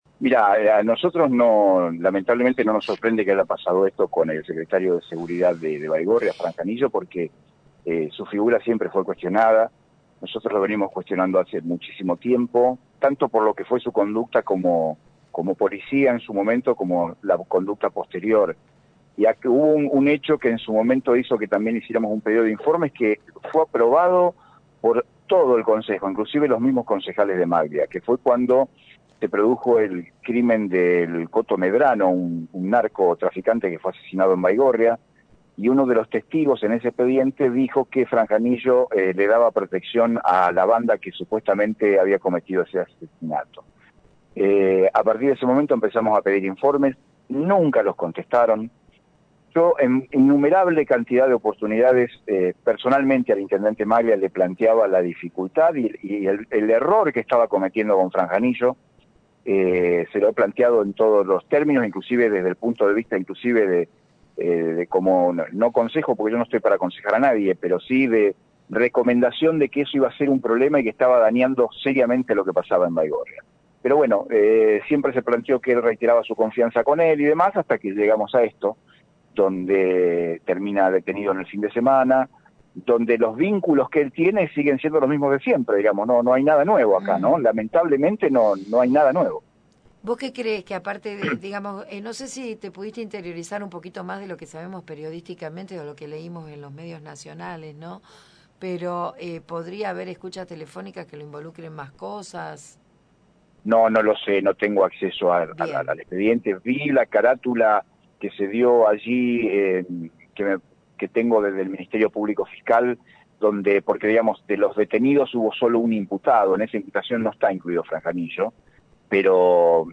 Javier Minetti, concejal opositor, se expresa en «Un día de Gloria»